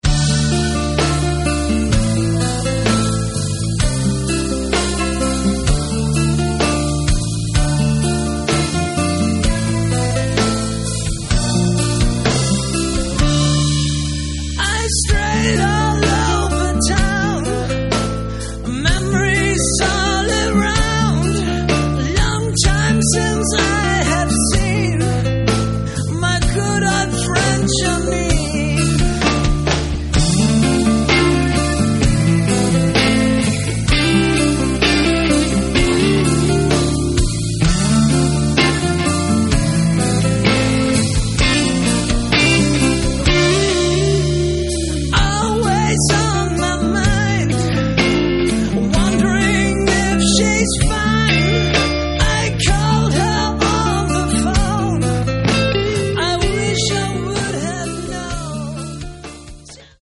Рок
вокал, гитары
бас
ударные
клавишные